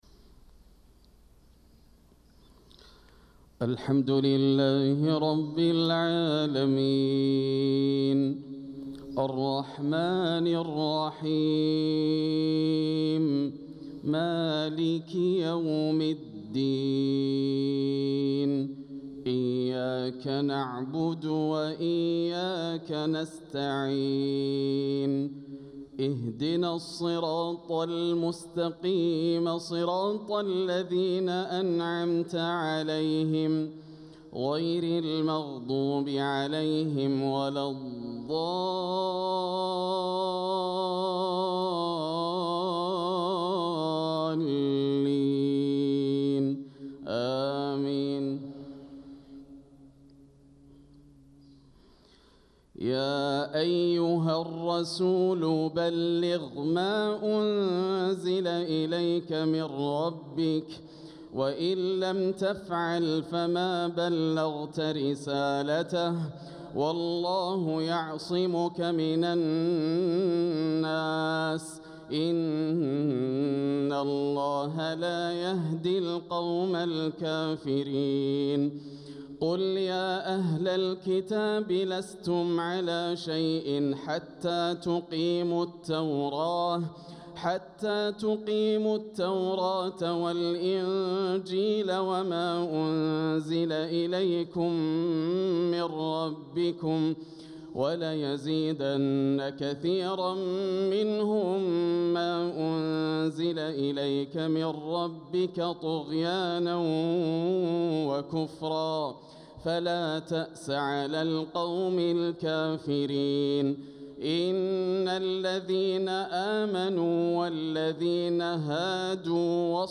صلاة الفجر للقارئ ياسر الدوسري 3 صفر 1446 هـ